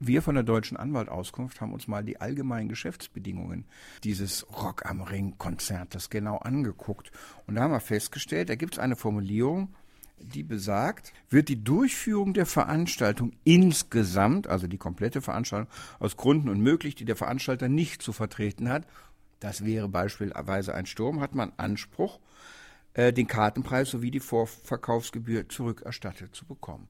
DAV, O-Töne / Radiobeiträge, Ratgeber, Recht, , , , ,